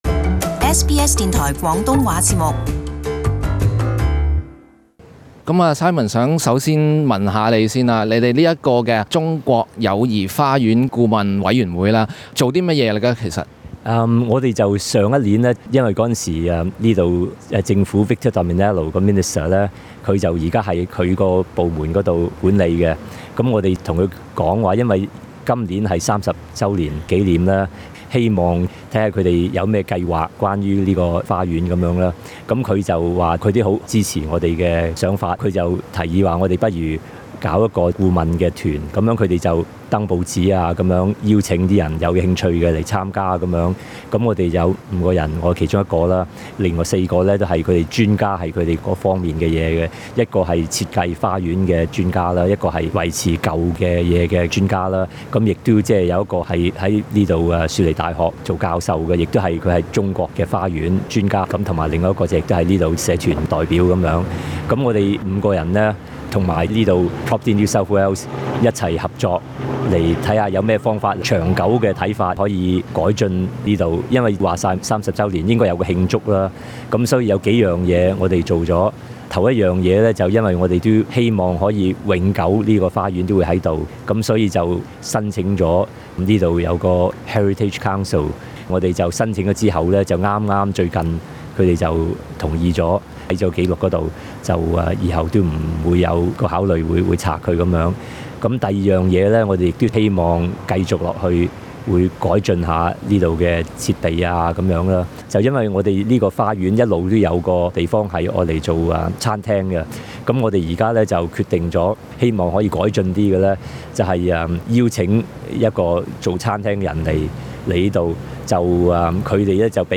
【雪梨社区专访】「谊园」获列新州文化遗产